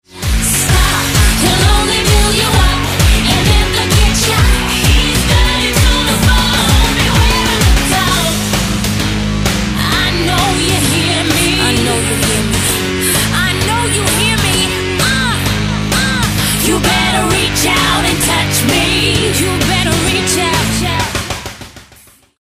brytyjskiej wokalistki